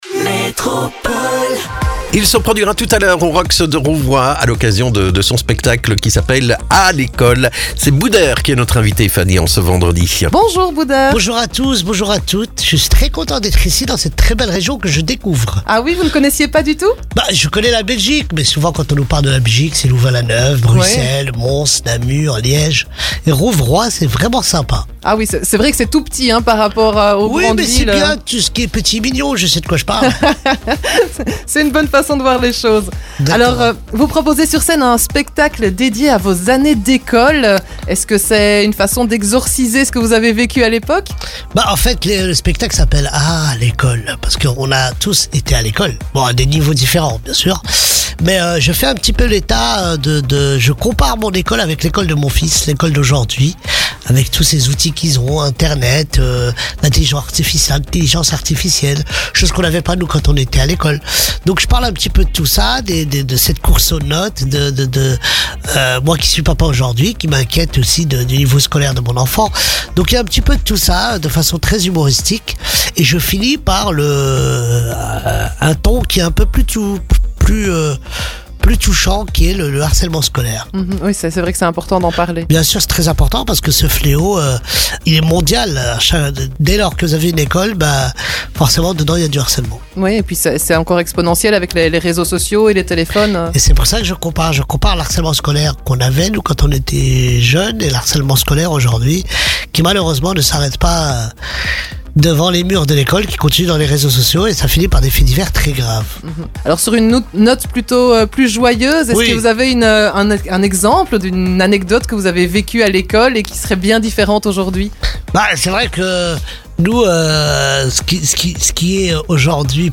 Il revient pour un deuxième soir ce vendredi et nous a accordé quelques minutes pour évoquer son spectaclé dédié à l'école...